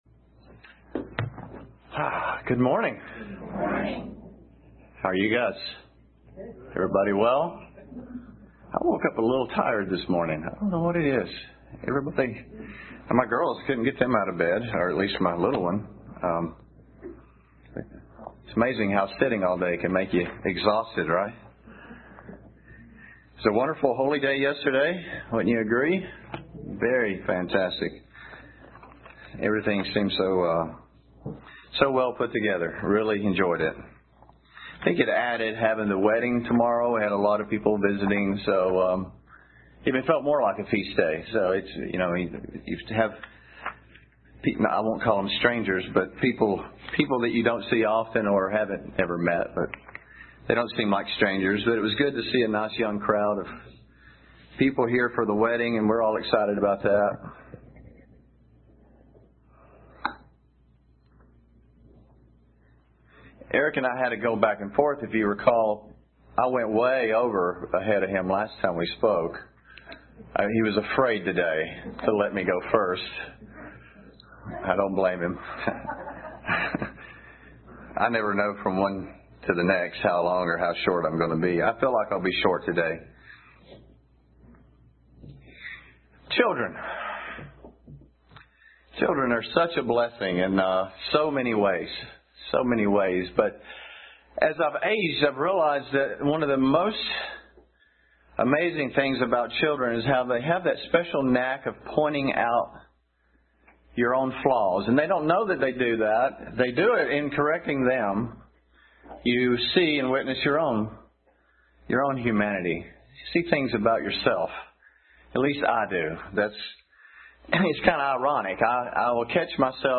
UCG Sermon Studying the bible?
Given in Murfreesboro, TN